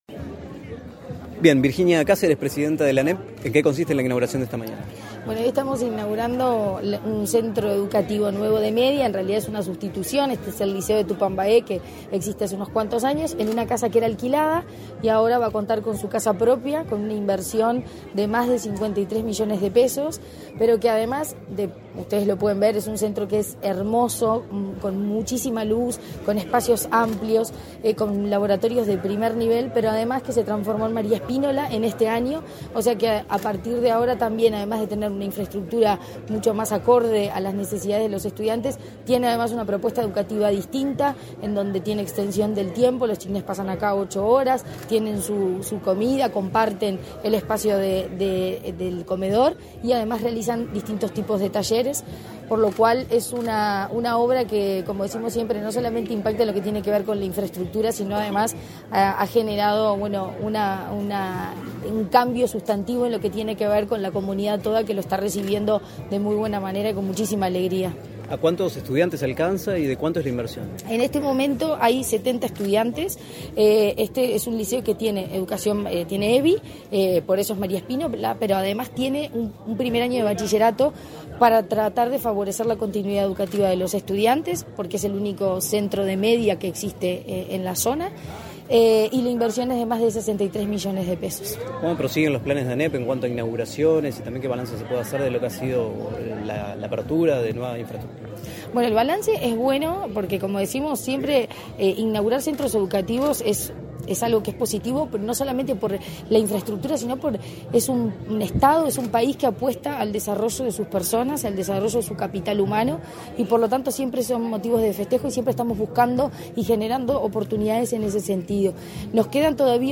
Entrevista a la presidenta de la ANEP, Virginia Cáceres